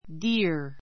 díə r ディ ア